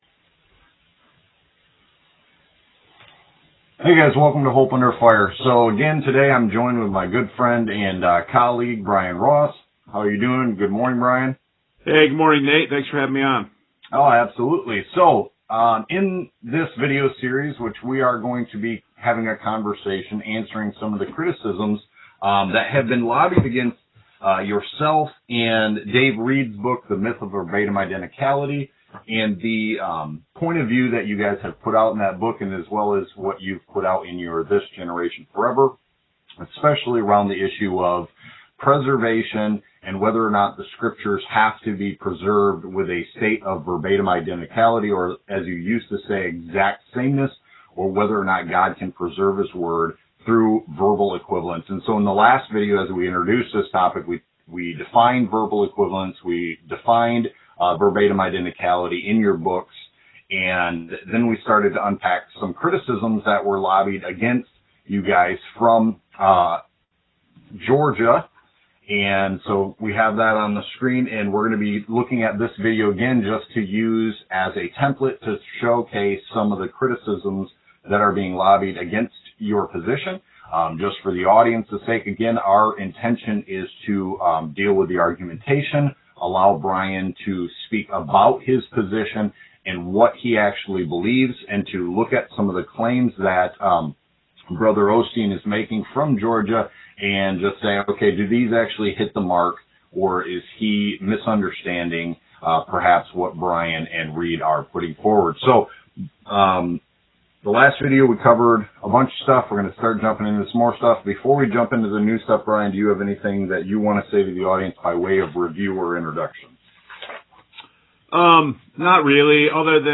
Conversation-answering-criticisms-of-Verbatim-Identicality_-Preservation-and-Verbal-Equivalence_.m4a